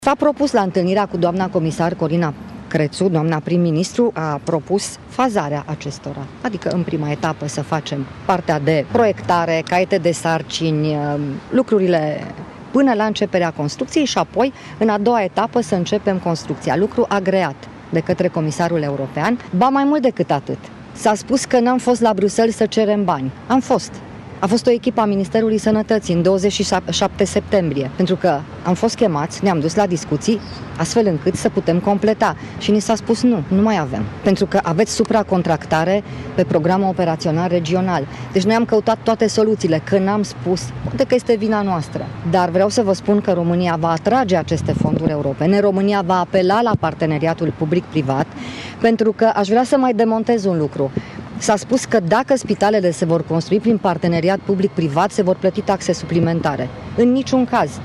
Ministrul sănătății Sorina Pintea a mai declarat că a avut discuții, la Bruxelles, în vederea suplimentării fondurilor europene pentru spitale, dar răspunsul a fost unul negativ: